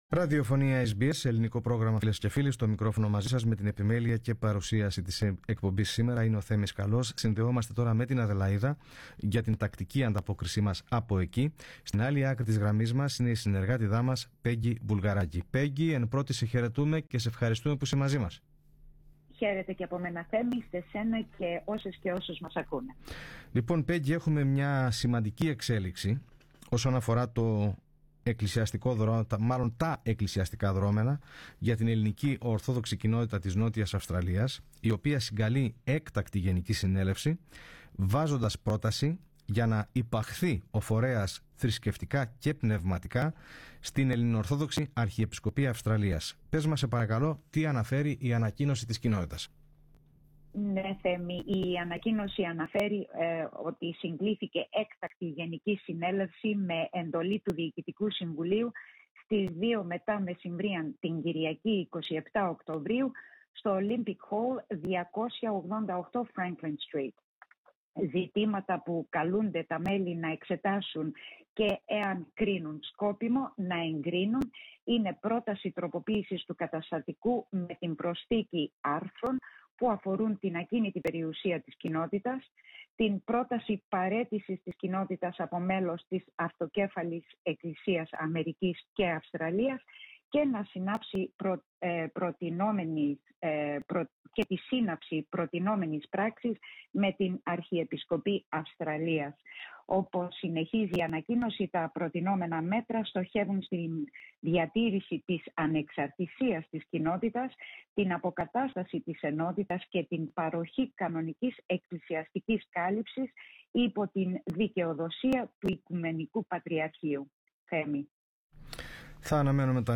Ακούστε τα υπόλοιπα θέματα της ανταπόκρισης από την Αδελαΐδα, πατώντας PLAY δίπλα από την κεντρική φωτογραφία.